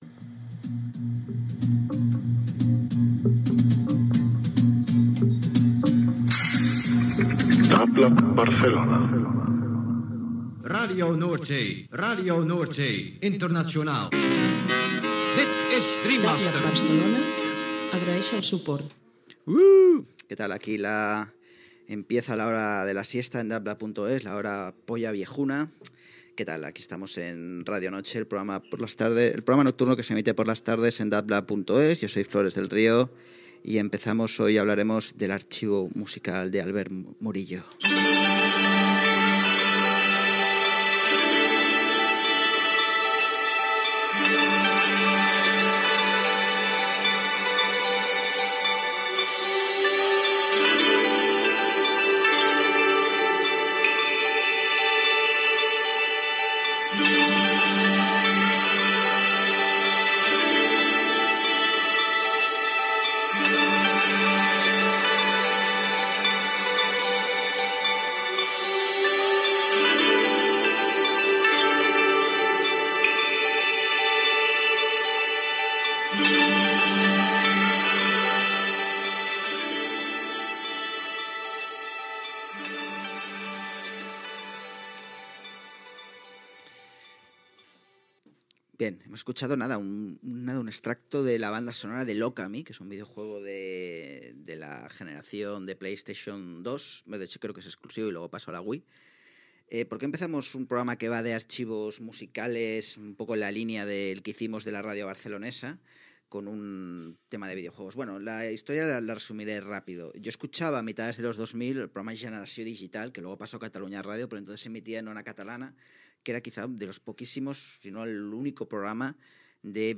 Indicatiu de la ràdio, careta del programa